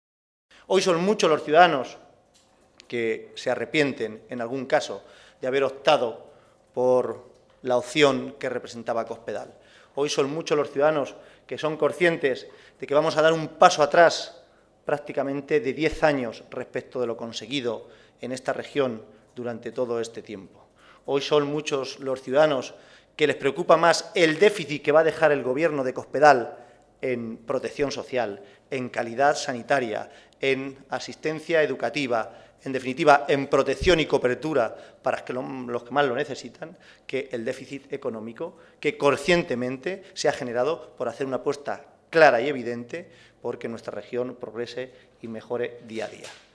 Caballero se pronunciaba así al comparecer ante los medios de comunicación para informar sobre el Comité Regional que los socialistas han celebrado esta tarde en Toledo.